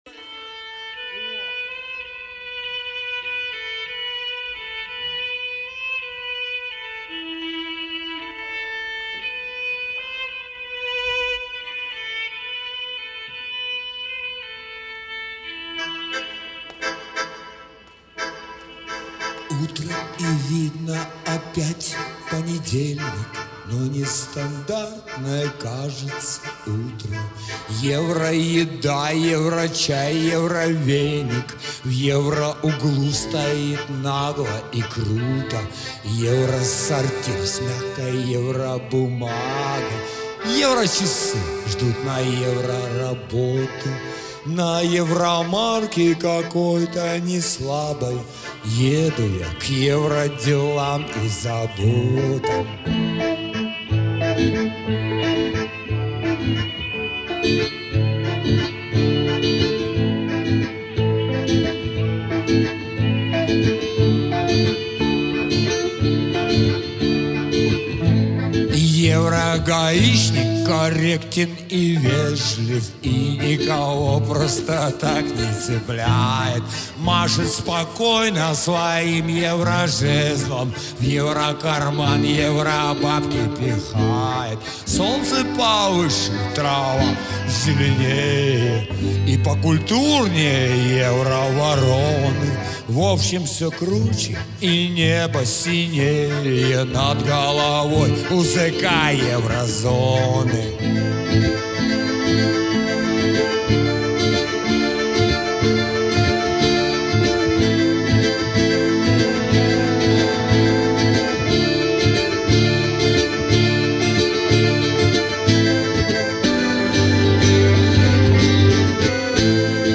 Потому не судите строго-это не студийные вылизанные записи-это как в жизни- по всякому.